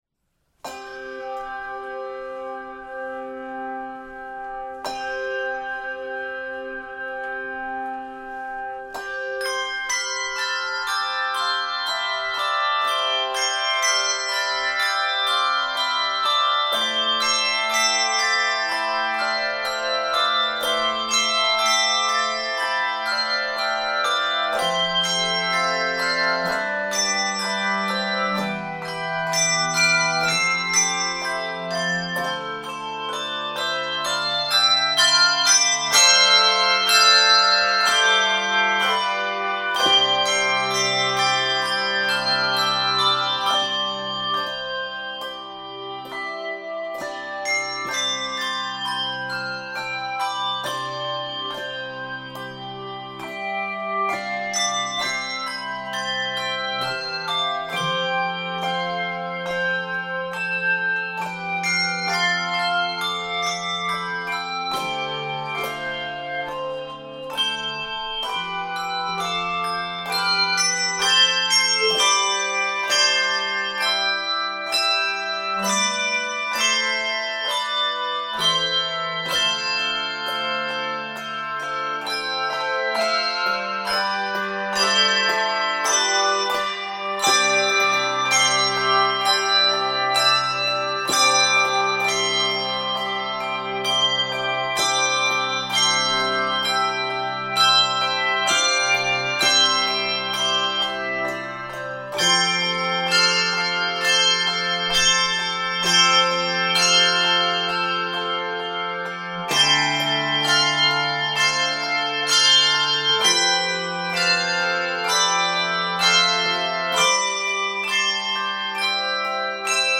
Keys of C Major and Ab Major.